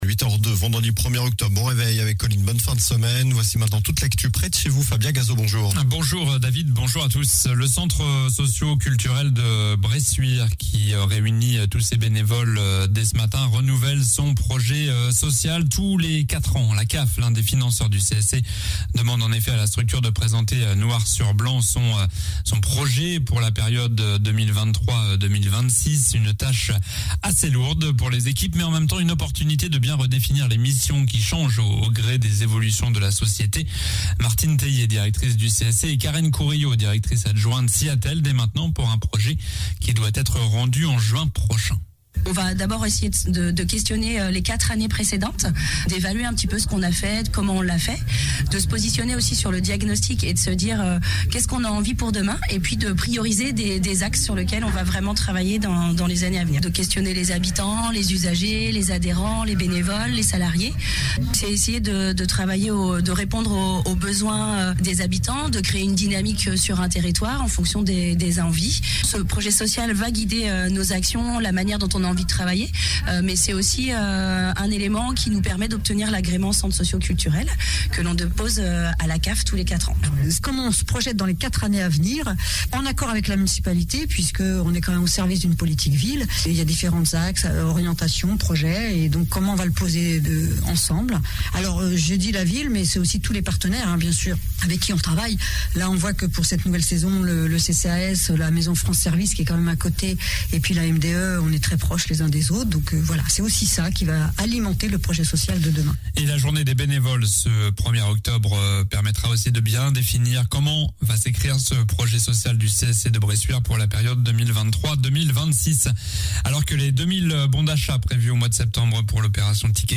Journal du vendredi 1er octobre (matin)